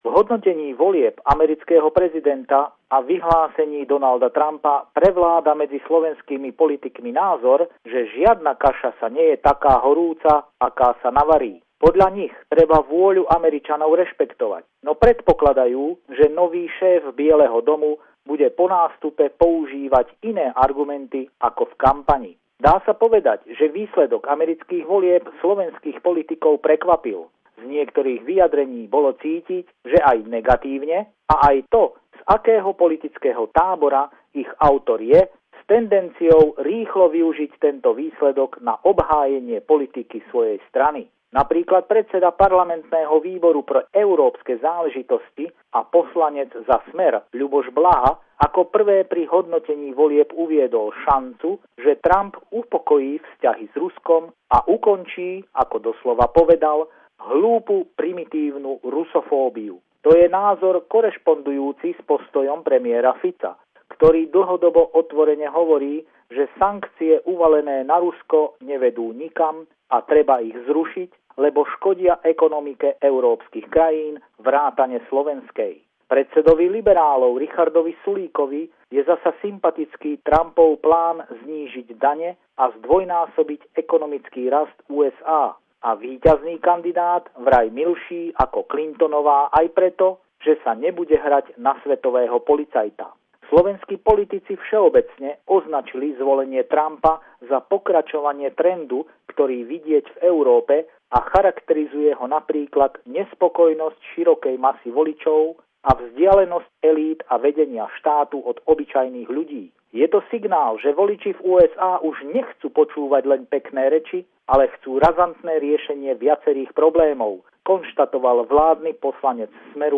Pravidelný telefonát týždňa